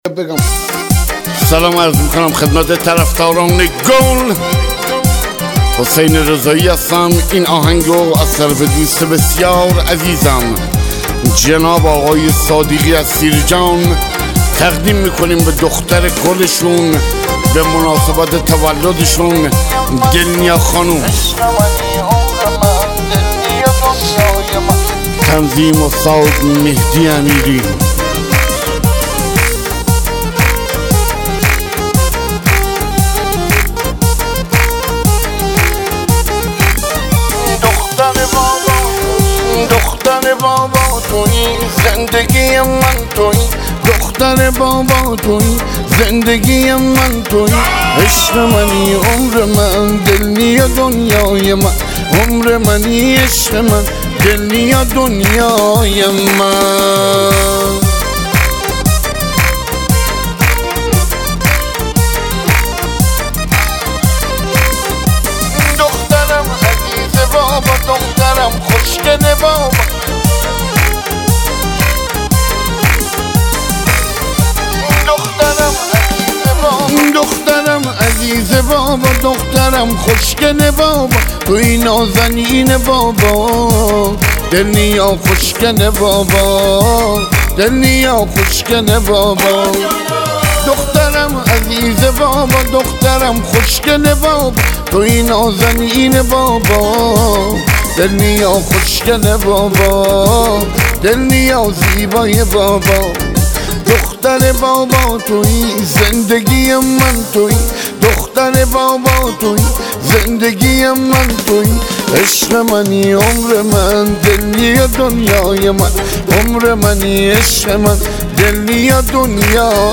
آهنگ جدید زیبای مازندرانی برای تولد روز دختر